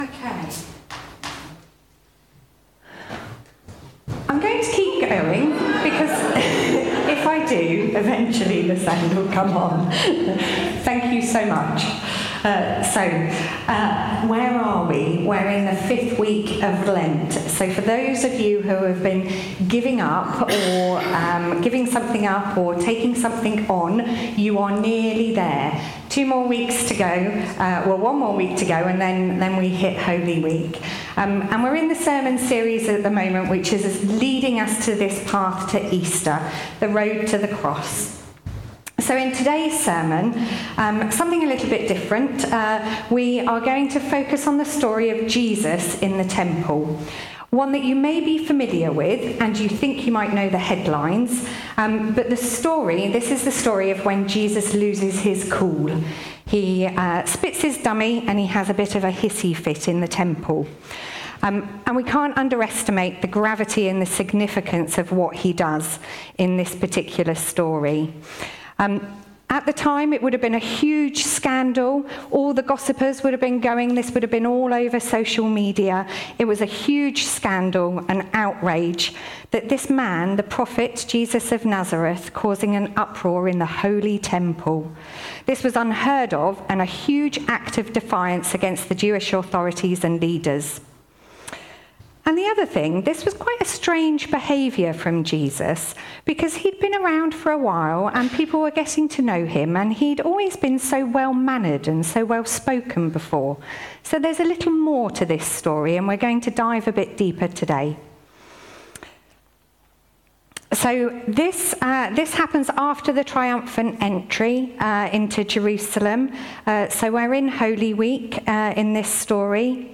Talks and Sermons - Thornhill Baptist Church